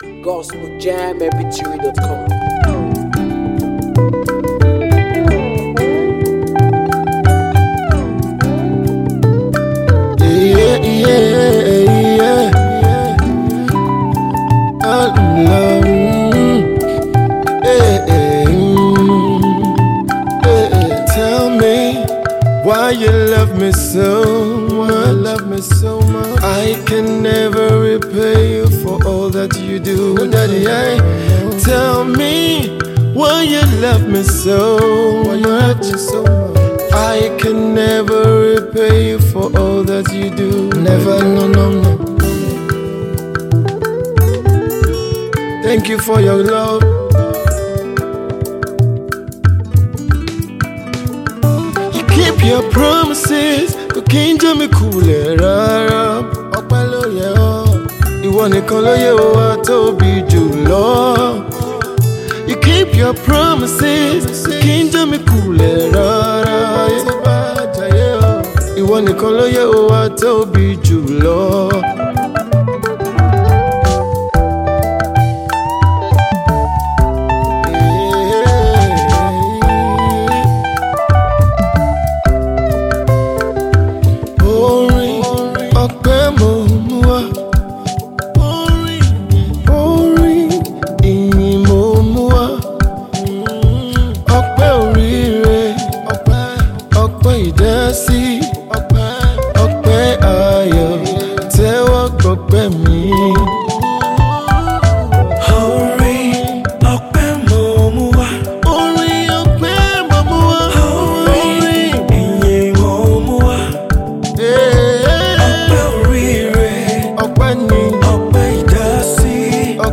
heartfelt song of gratitude to God
it is worship, testimony, and unending praise.